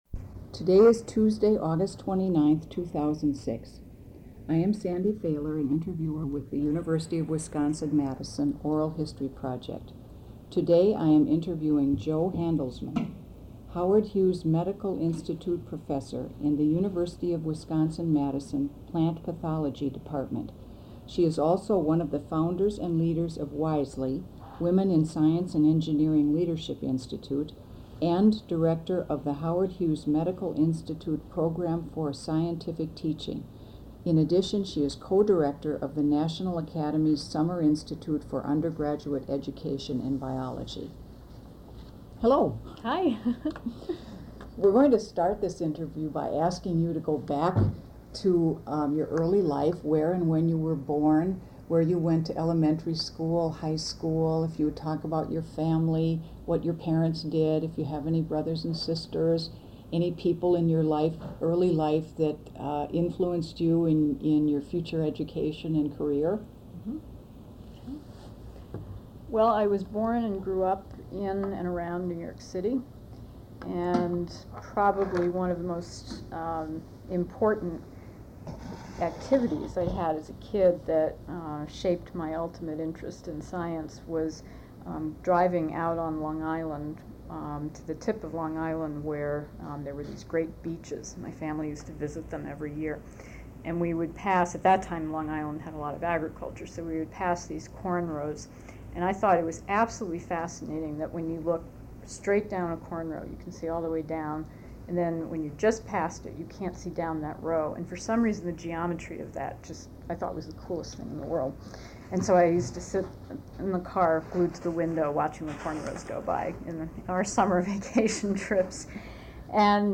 Oral History Interview: Jo Handelsman